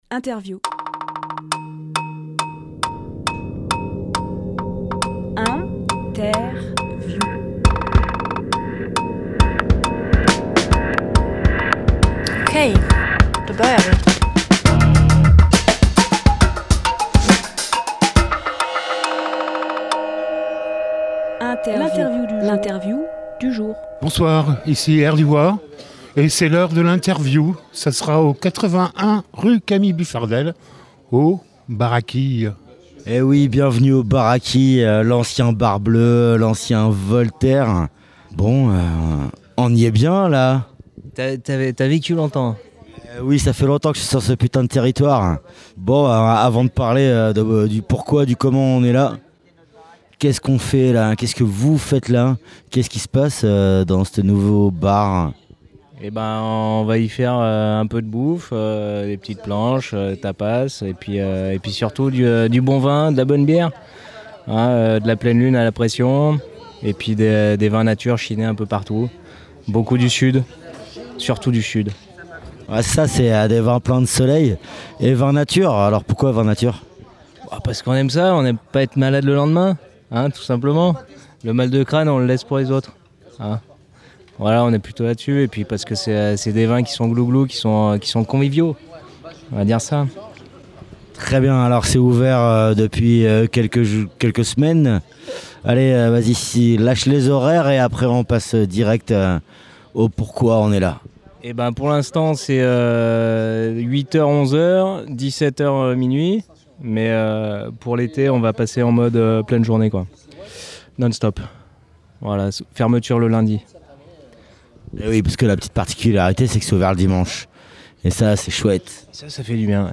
Emission - Interview
Lieu : Le Baraquille, Die